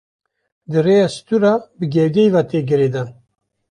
/ɡɪɾeːˈdɑːn/